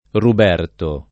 DOP: Dizionario di Ortografia e Pronunzia della lingua italiana
Ruberto [ rub $ rto ] → Roberto